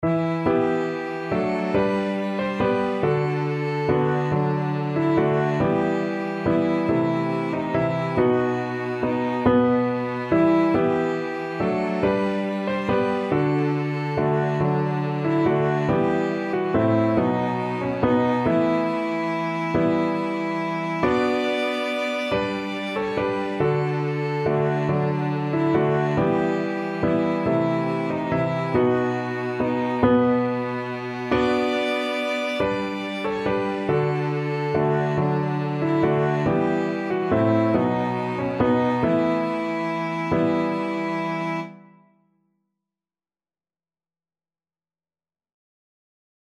ViolinCelloPiano